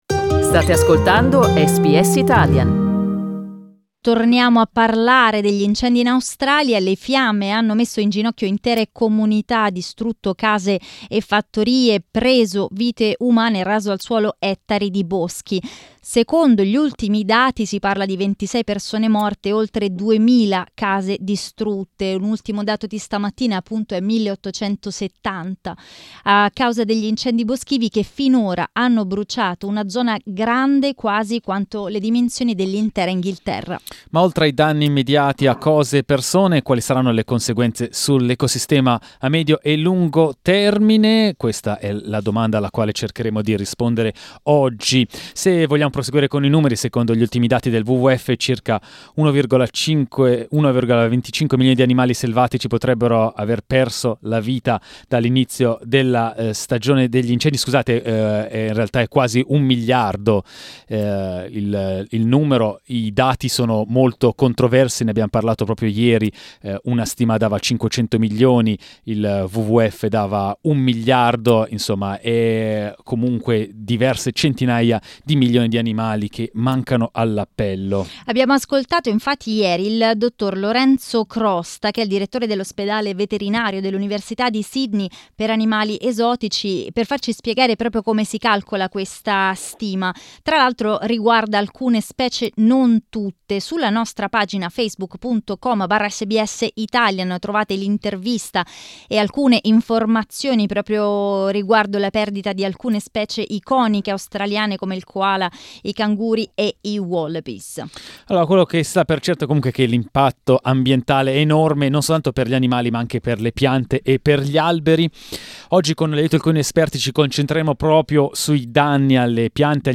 In this morning's program (9 January) we have asked our listeners to talk about the devastation of the bushfires.